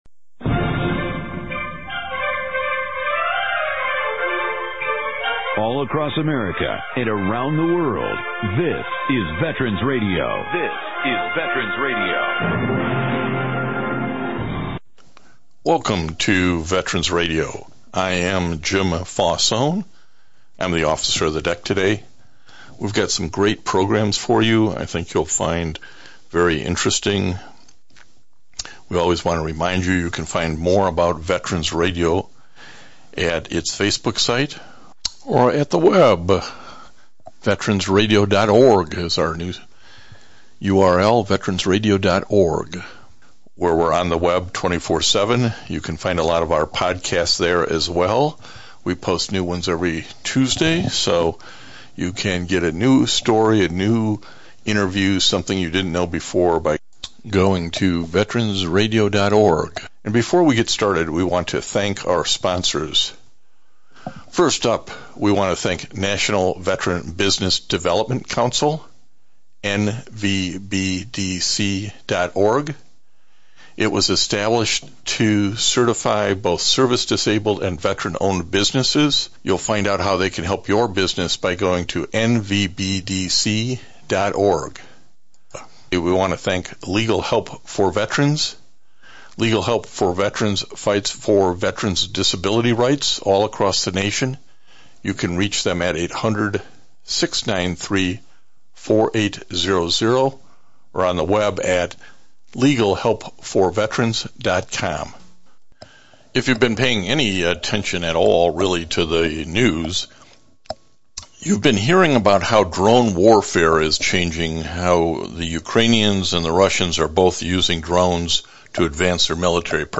three interviews